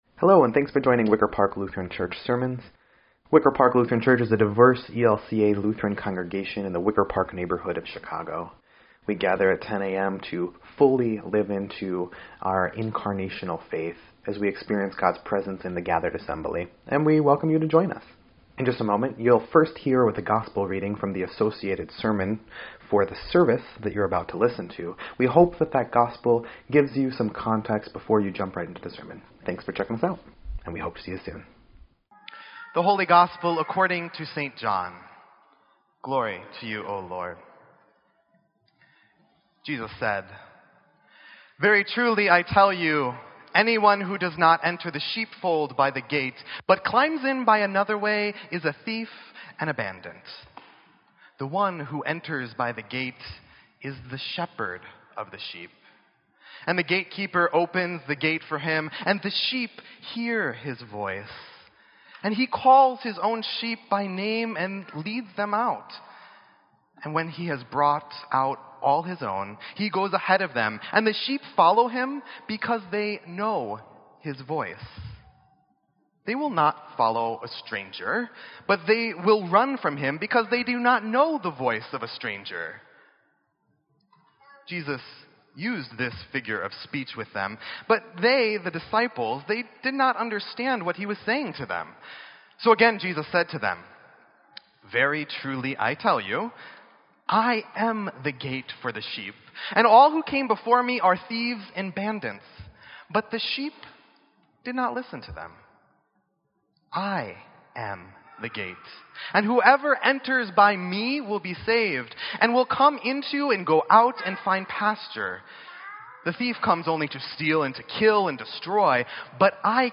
Sermon_5_7_17_EDIT.mp3